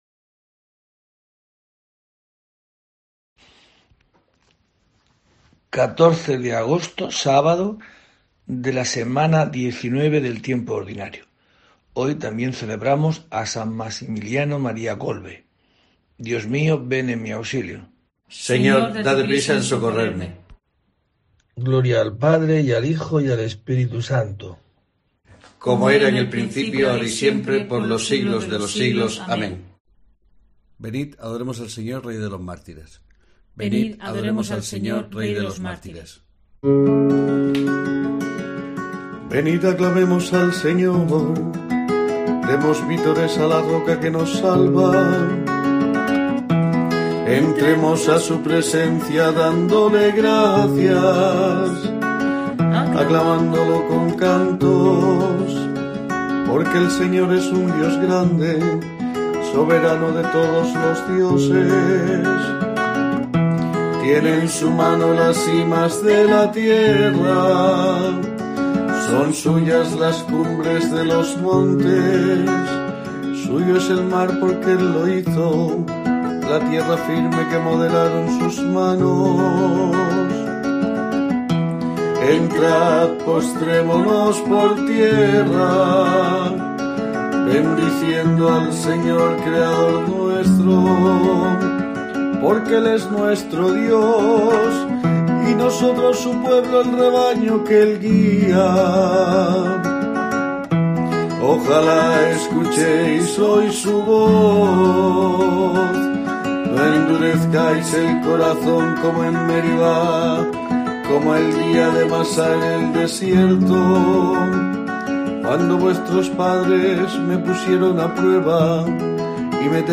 14 de agosto: COPE te trae el rezo diario de los Laudes para acompañarte